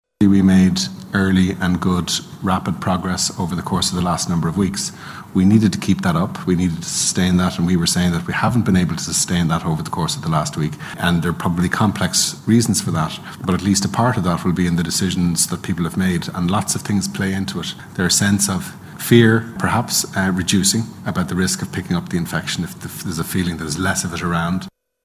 Dr Tony Holohan says people have taken their “foot off the gas”.